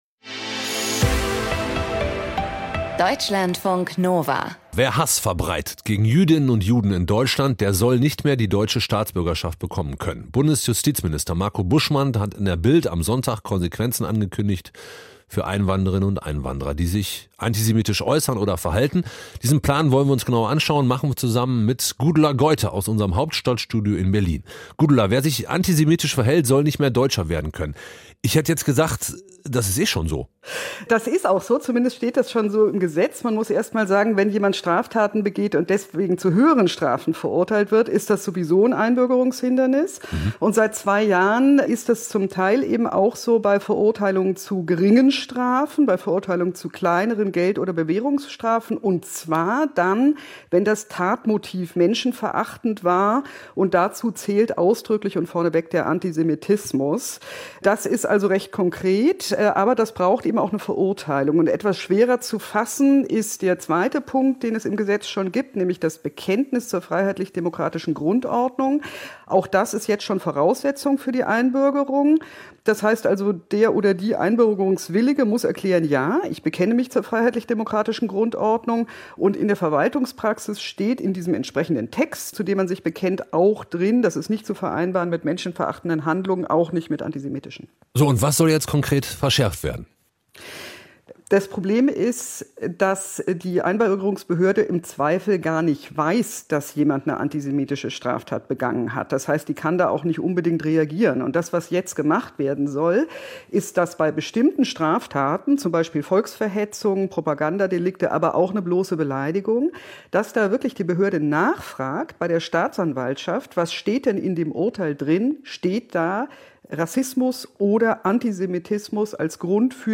Kommentar - Der Antisemitismus der Anderen